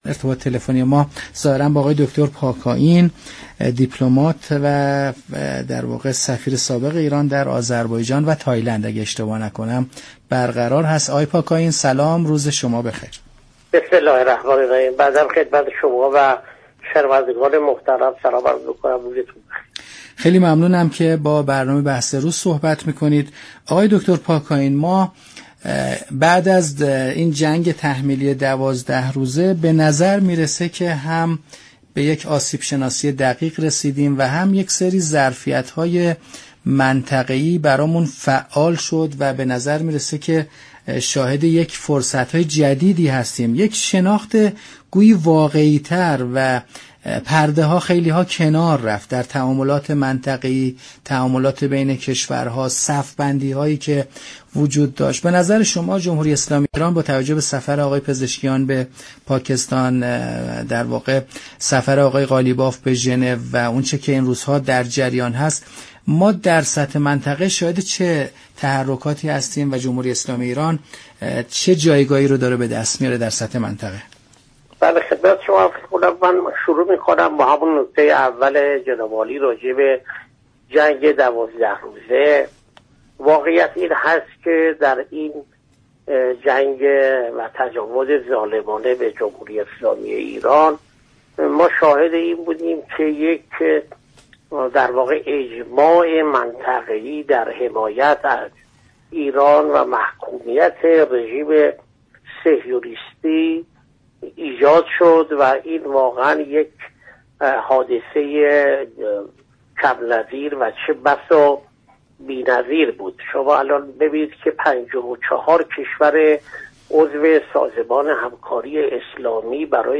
‌ دیپلمات و سفیر سابق ایران در آذربایجان در برنامه بحث‌روز گفت:54 كشور عضو سازمان همكاری‌های اسلامی برای اولین بار با ارسال بیانیه مستقل رژیم صهیونیستی را محكوم كردند.